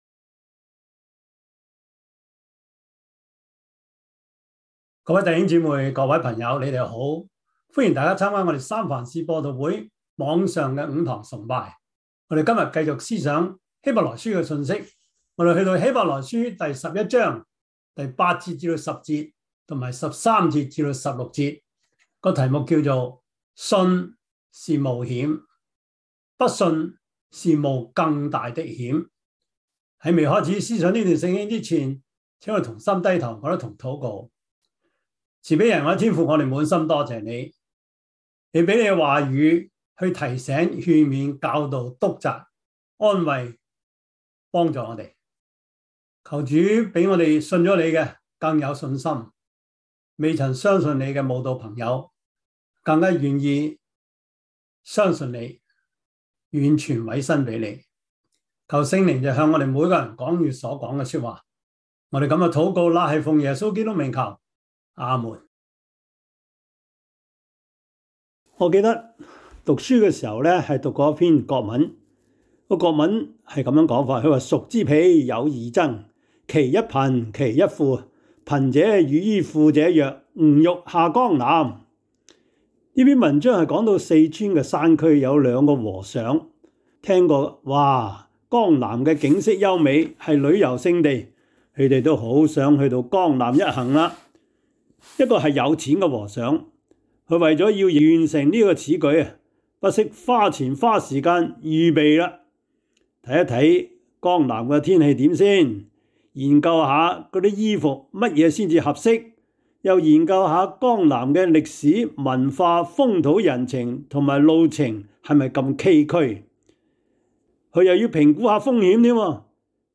Service Type: 主日崇拜
Topics: 主日證道 « 以馬內利 哀號 »